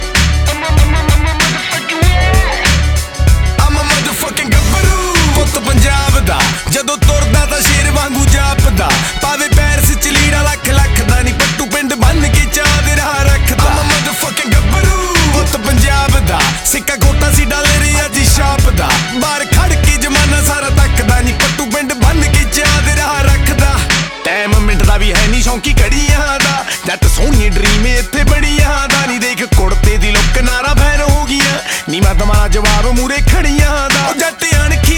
Rock Hip-Hop Rap
Жанр: Хип-Хоп / Рэп / Рок